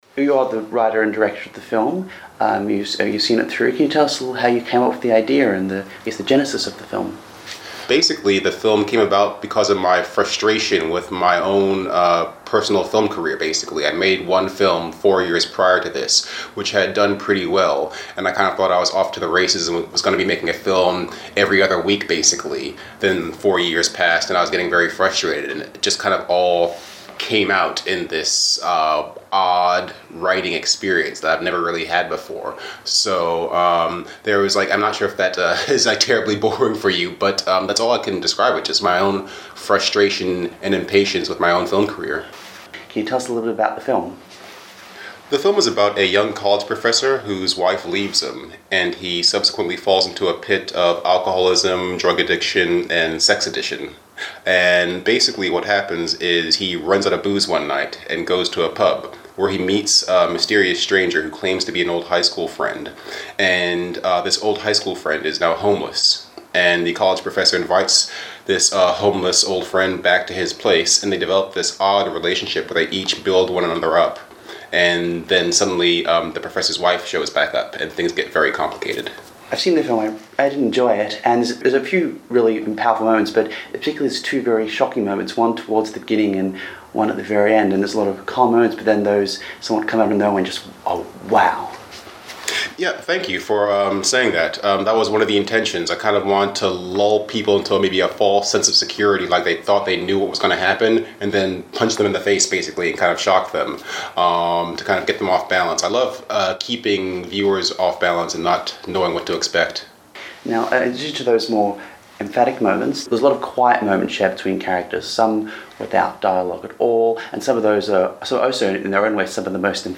Festivals, Interviews